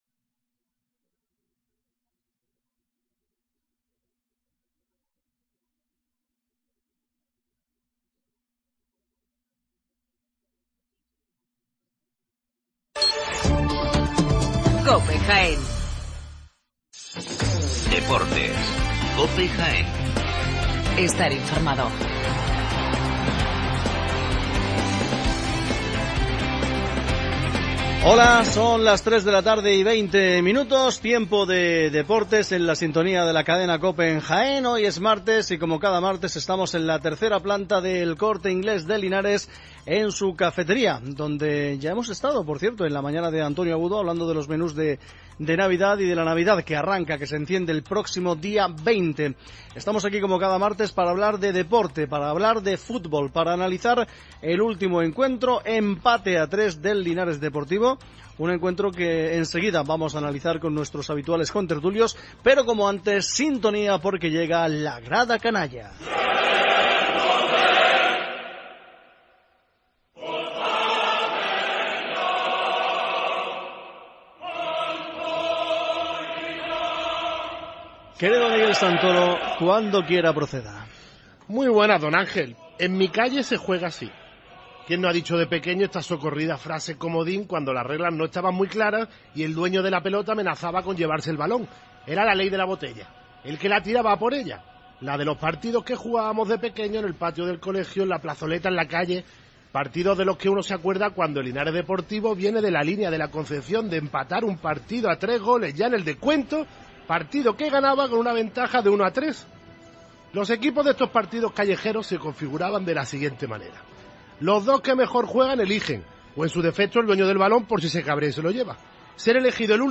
Tertulia deportiva desde Linares 10 de noviembre de 2015